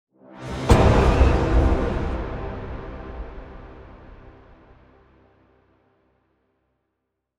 player_dead.wav